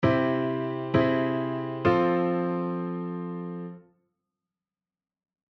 例：I⇒Iaug⇒IV（C⇒Caug⇒F）
C⇒Caug⇒F